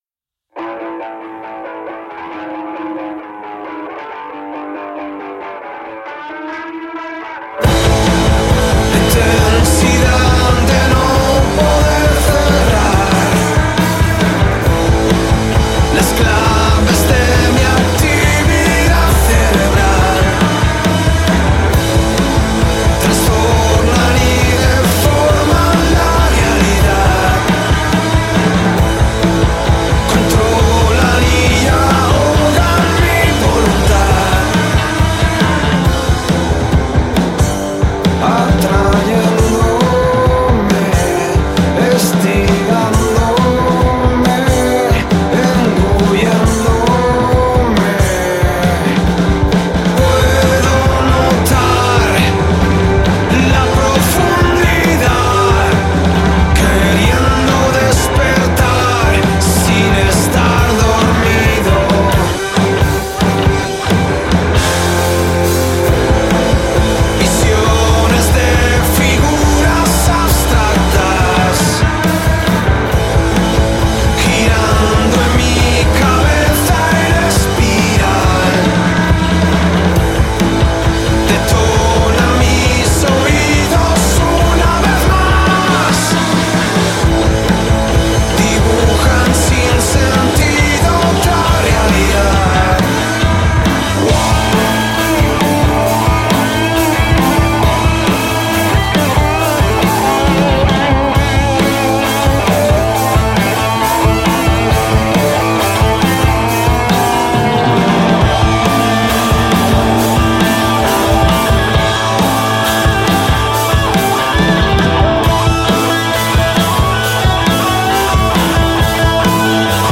five-piece Barcelona Spain based grunge-rock band